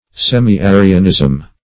Search Result for " semi-arianism" : The Collaborative International Dictionary of English v.0.48: Semi-Arianism \Sem`i-A"ri*an*ism\, n. The doctrines or tenets of the Semi-Arians.
semi-arianism.mp3